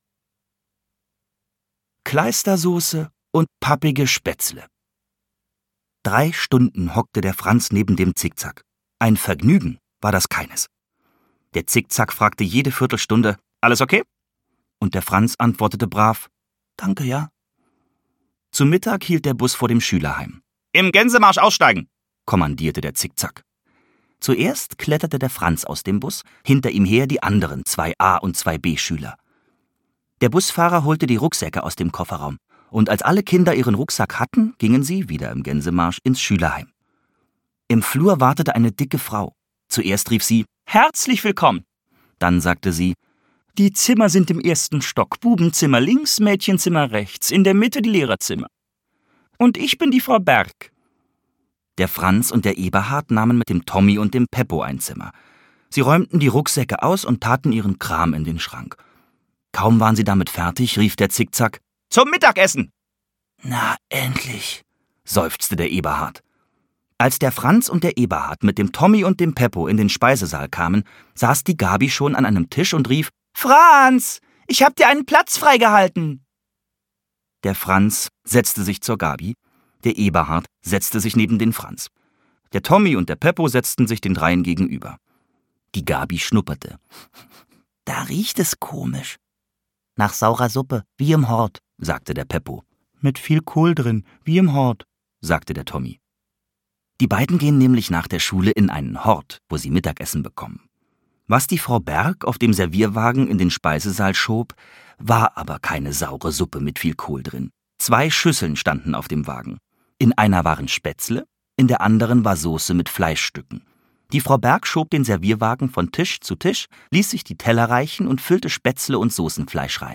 Franz auf Klassenfahrt - Christine Nöstlinger - Hörbuch